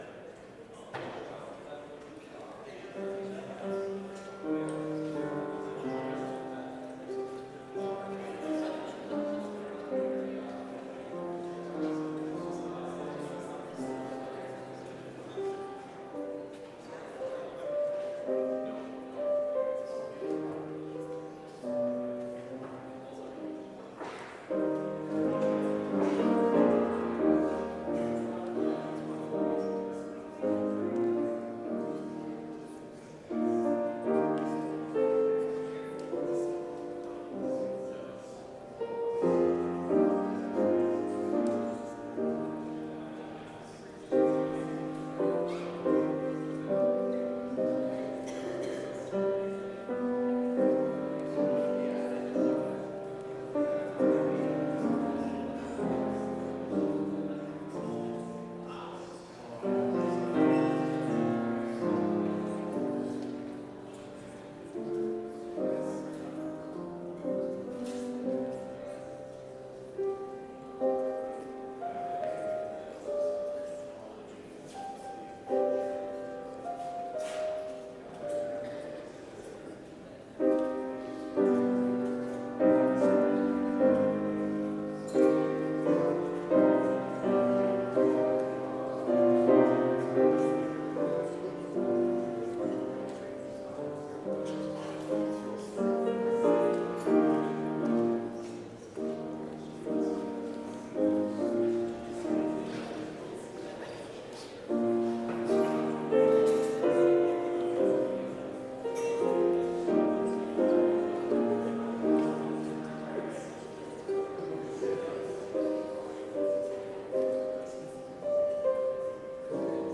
Complete service audio for Chapel - November 17, 2022